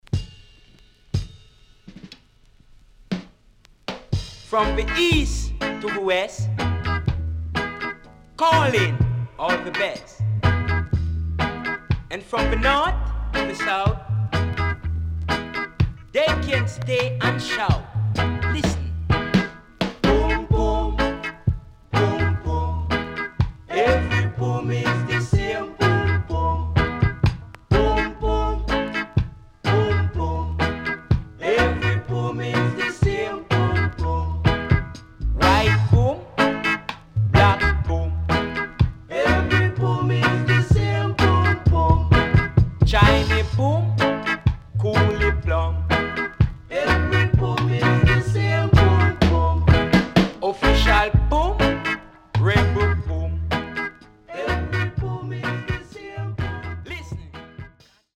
HOME > REGGAE / ROOTS
SIDE A:少しチリノイズ入りますが良好です。